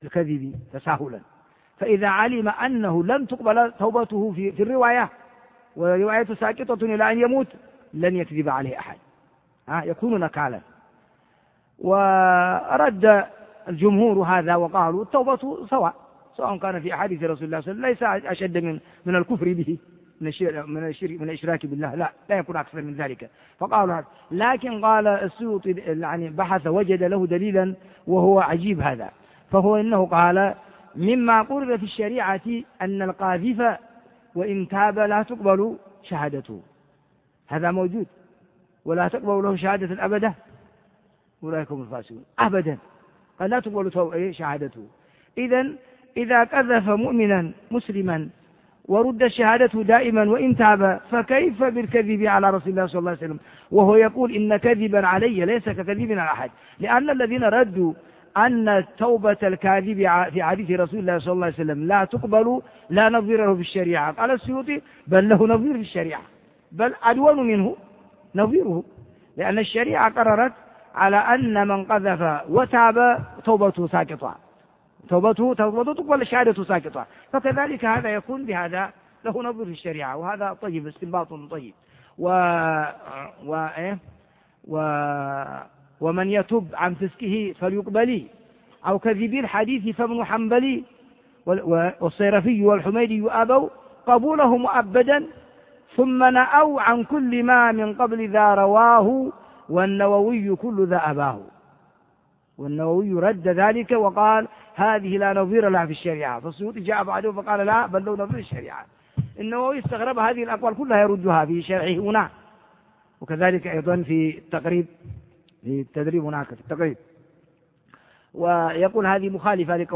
شرح صحيح مسلم الدرس 8